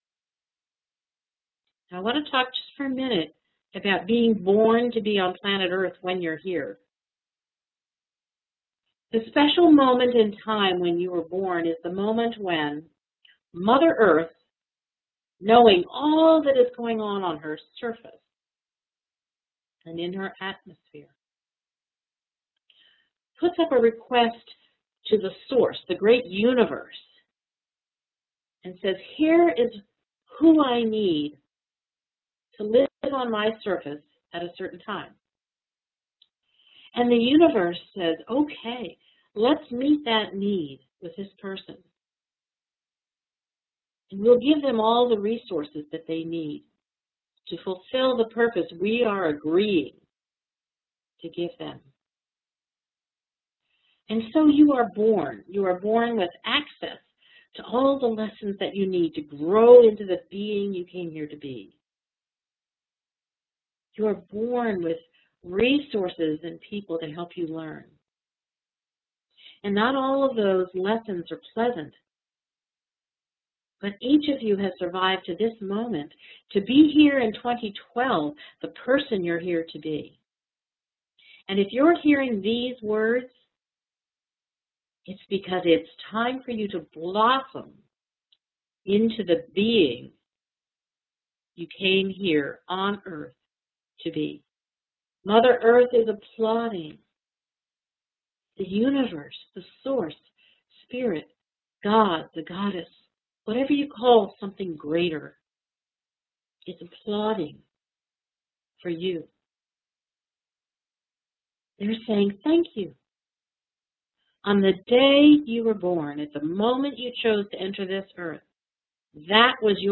Here is a 5-minute audio sample of a recent teachings: